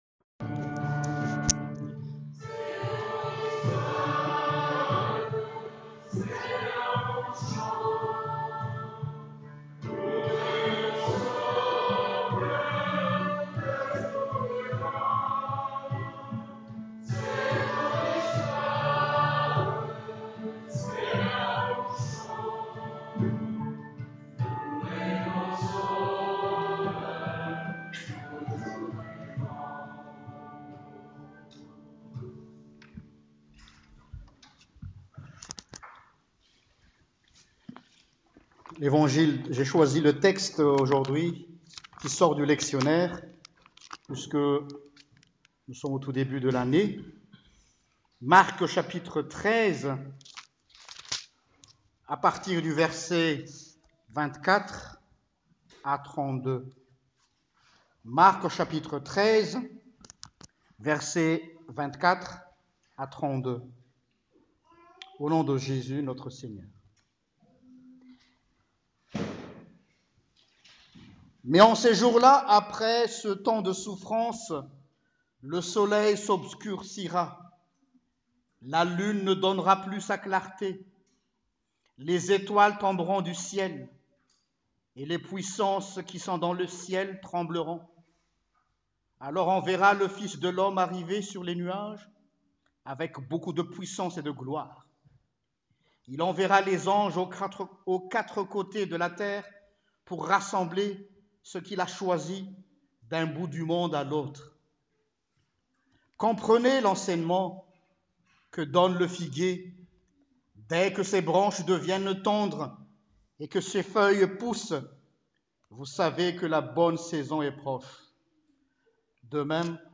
Prédication-du-08-Septembre.mp3